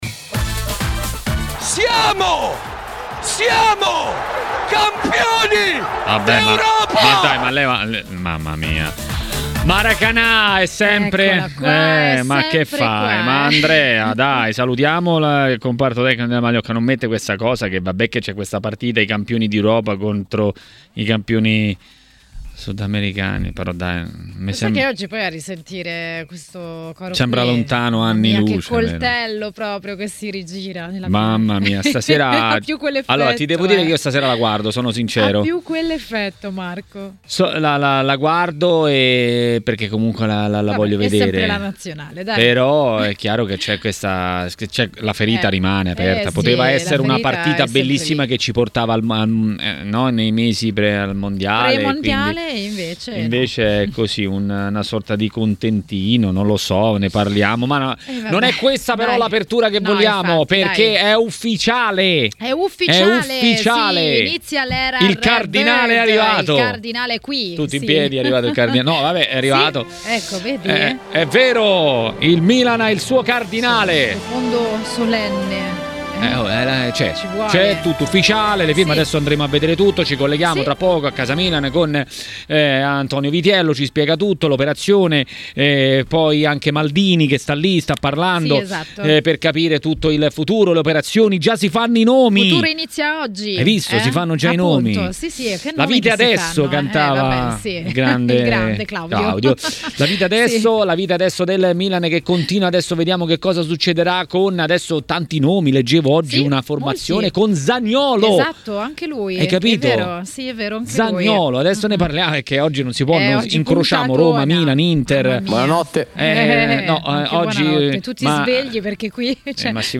Per commentare le notizie del giorno a Tmw Radio, è intervenuto l'ex attaccante del Napoli Giuseppe Incocciati.